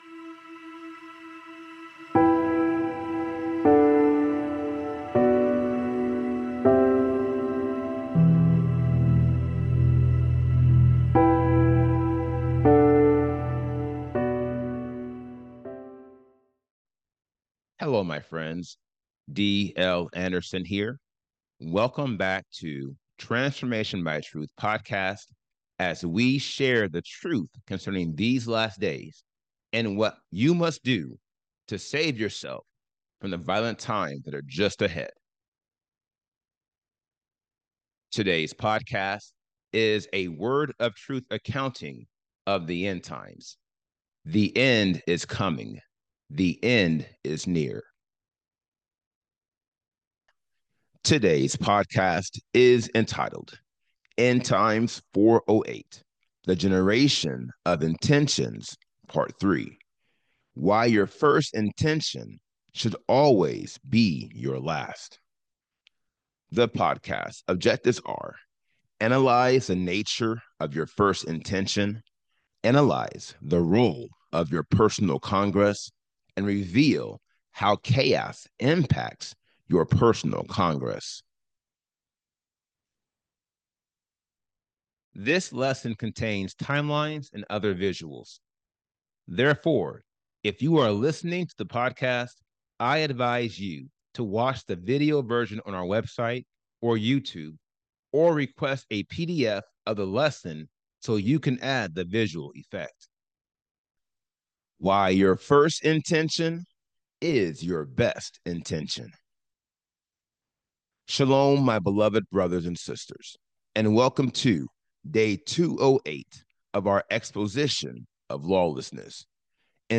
This podcast is a 400-level lecture dedicated to analyzing the 80 degrees of lawlessness and showing you how you can eliminate each one to obtain the Seal of Elohim. Its purpose is to analyze the nature of your first intention, analyze the role of your personal congress, and reveal how chaos impacts your personal congress.